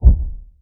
JSRS Sound Mod / gamedata / sounds / weapons / _bass / bass_exp.ogg
bass_exp.ogg